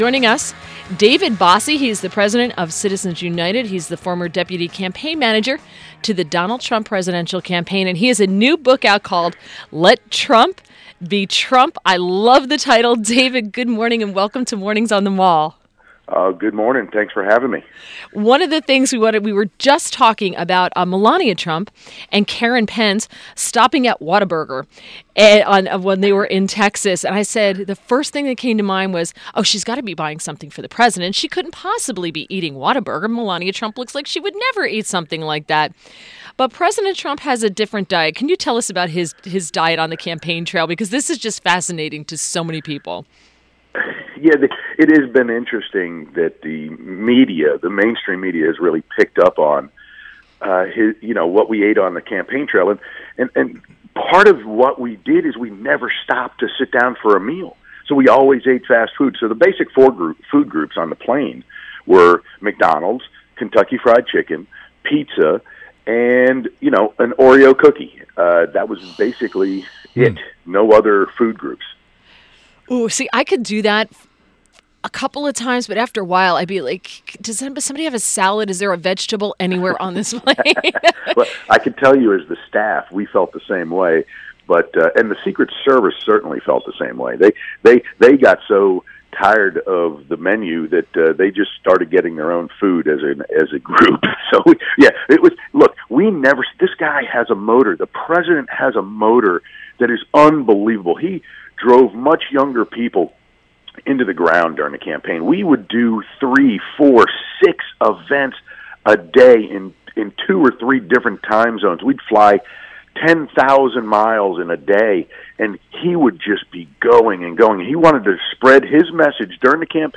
WMAL Interview - DAVID BOSSIE - 12.08.17
INTERVIEW — DAVID BOSSIE – President of Citizens United, former Deputy Campaign Manager to the Donald Trump presidential campaign and author of new book “Let Trump Be Trump”